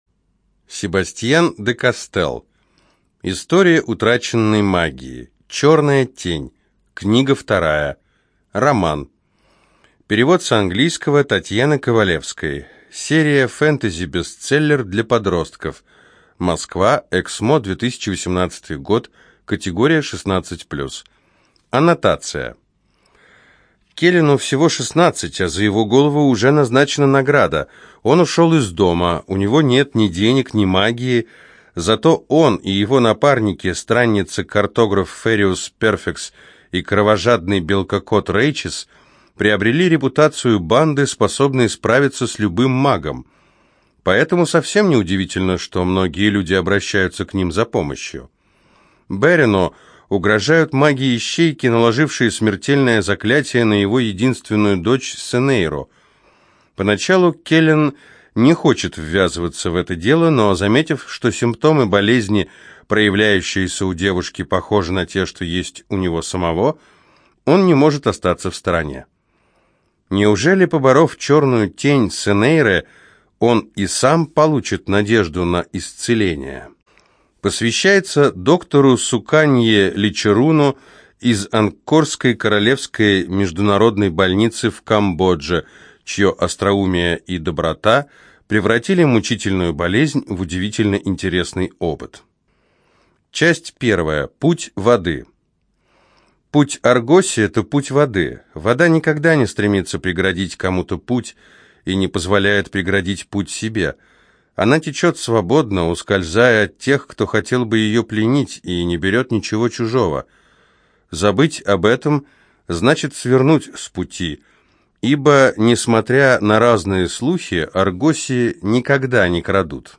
ЖанрФэнтези
Студия звукозаписиЛогосвос